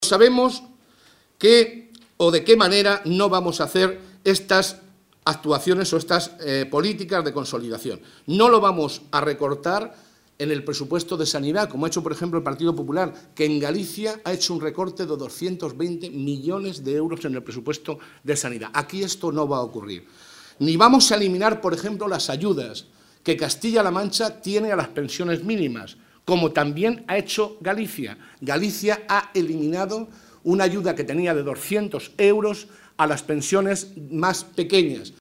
El portavoz del Grupo Parlamentario Socialista, José Molina, ha destacado, en una rueda de prensa en Ciudad Real, que el Gobierno de Castilla-La Mancha acometerá un “esfuerzo importante” a través del Plan de Consolidación de las Cuentas Públicas, aprobado esta misma mañana por el Consejo de Gobierno.